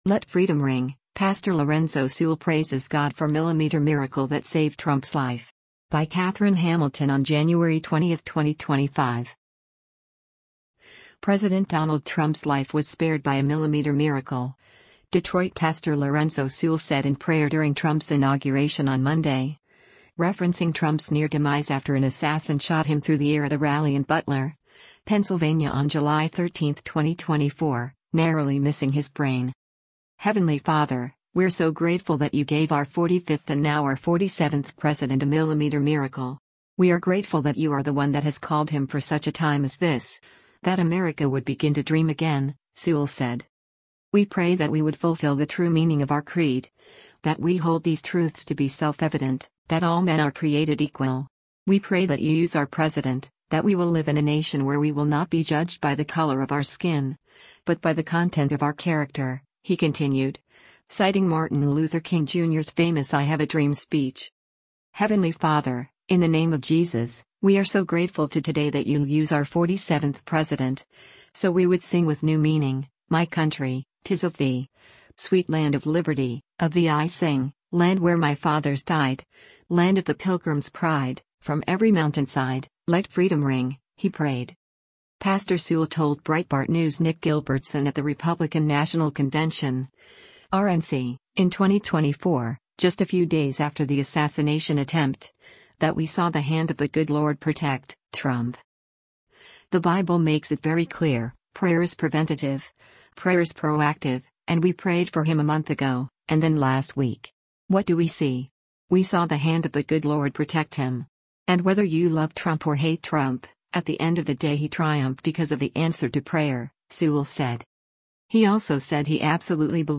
said in prayer during Trump’s inauguration on Monday